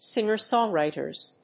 singer-songwriters.mp3